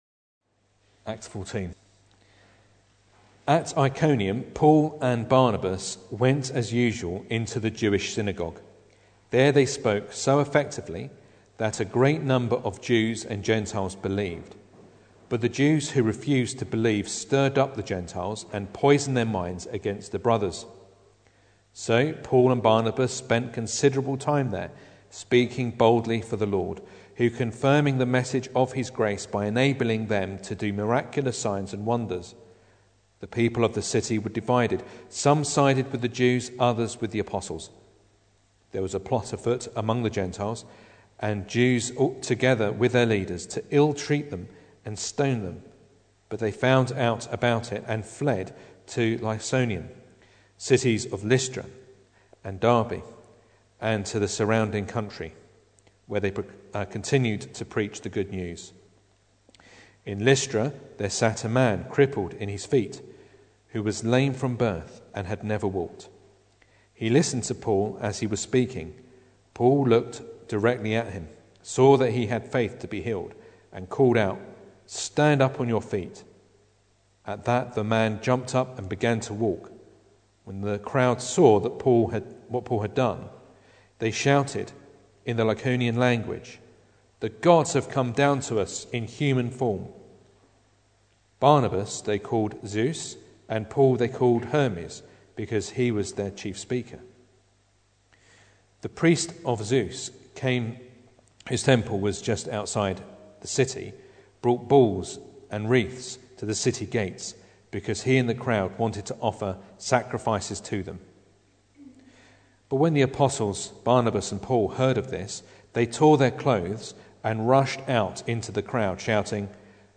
Acts 14 Service Type: Sunday Evening Bible Text